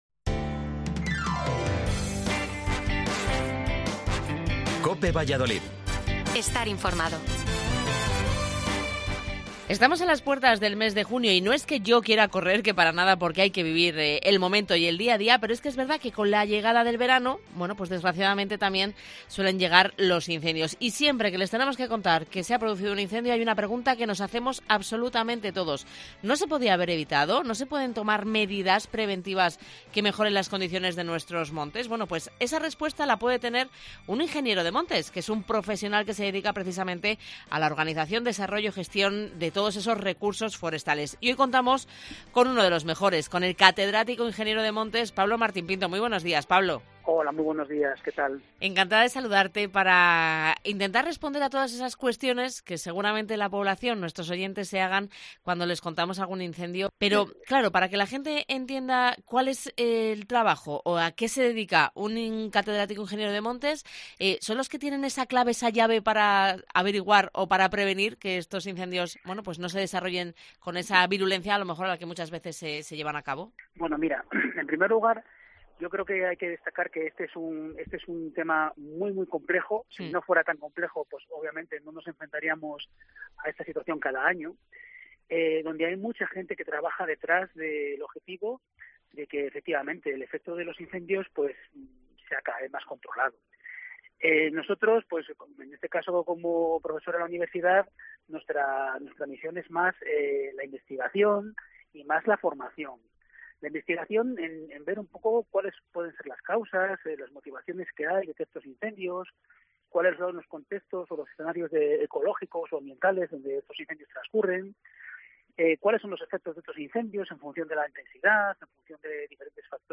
Explica en Herrera en COPE que estas lluvias generan vegetación que se transforma en material “fino seco”.